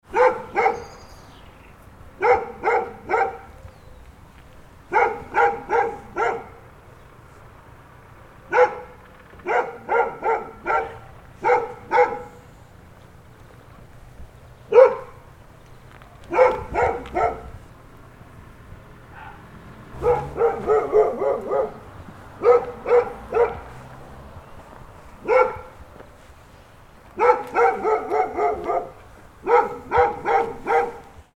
Lively Dog Barking Sound Effect
This rhythmic dog barking Sound Effect captures a lively dog in action, with clear, natural barks and realistic audio.
Bring authentic dog sounds, animal sounds, and subtle street ambience into your projects with this sound.
Lively-dog-barking-sound-effect.mp3